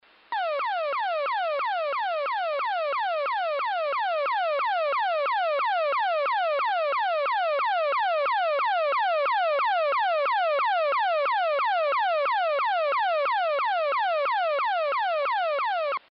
Сегодня принял на 243 АМ.
Похоже аварийный маяк на натовском самолете сработал.
100% аварийный маяк.